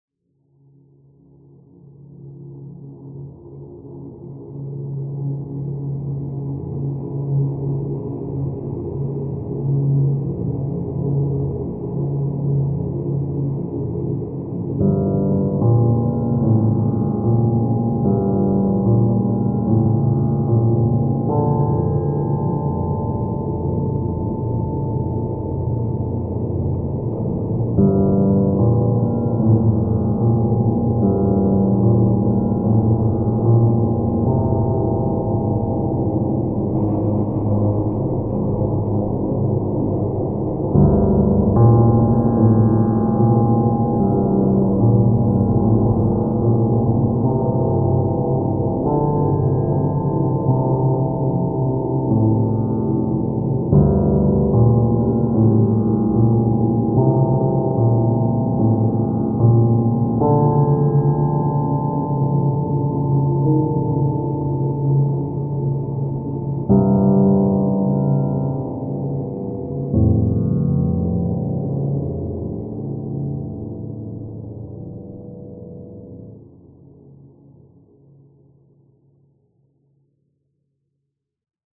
Piano, Atmospheric Drones Tempo - Medium Fast BPM - 117